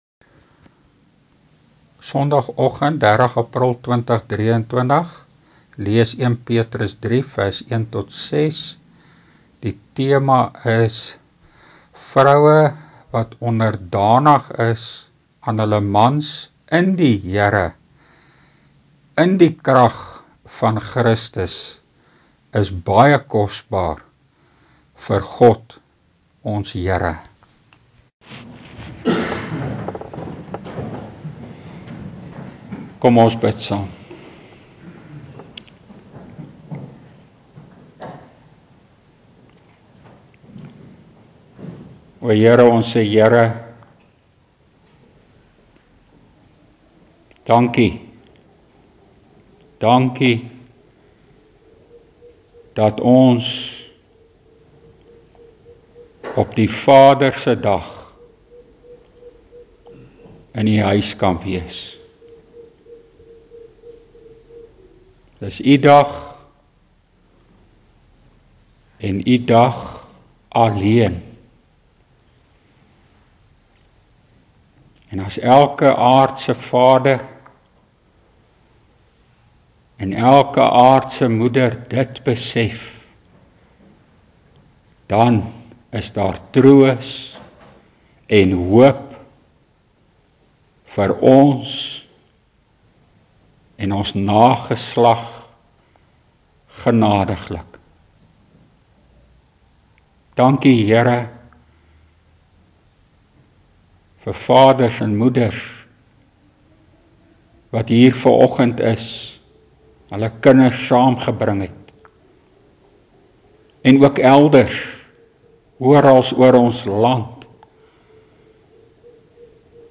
1 PETRUS 3 PREKE: (14)